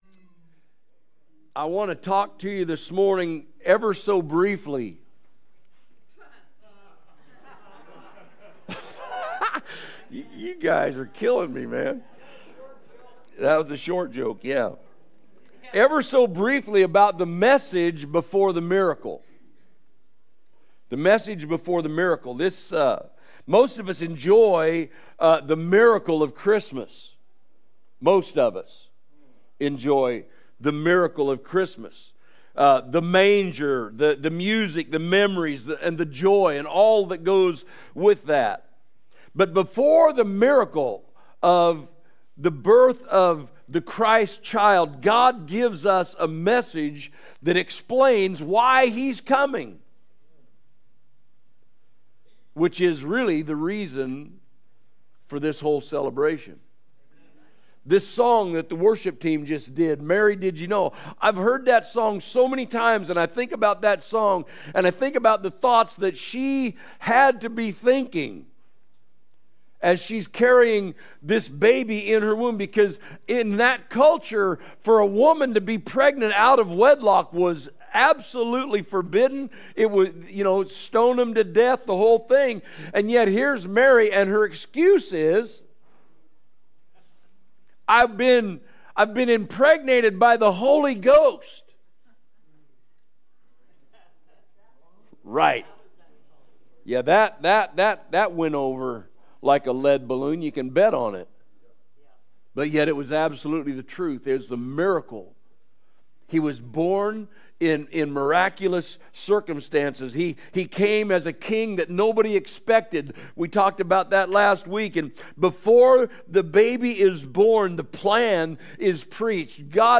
Sunday Morning Service December 14, 2025 – The Message before the Miracle
Recent Sermons